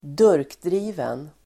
Ladda ner uttalet
Uttal: [²d'ur:kdri:ven]